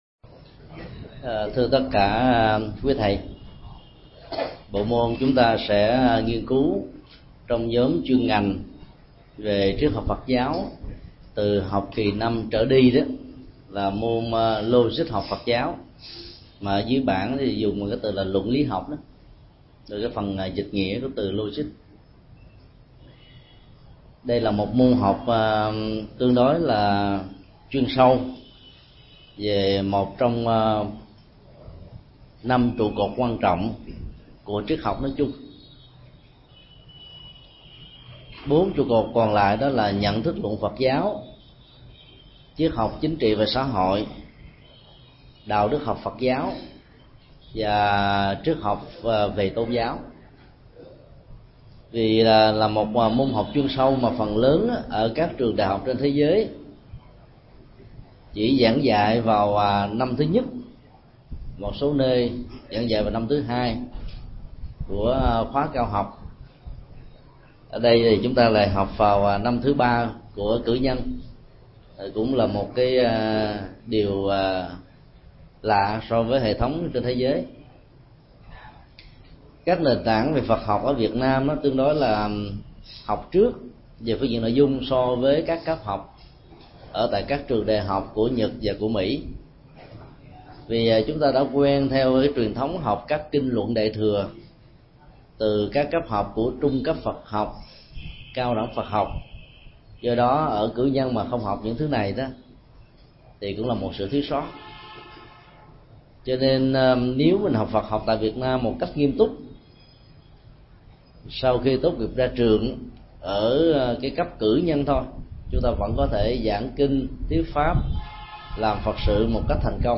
Mp3 Bài Giảng Logic 1: Tư duy logic – Thượng Tọa Thích Nhật Từ Giảng tại Học viện Phật giáo Việt Nam tại TP.HCM, ngày 26 tháng 8 năm 2007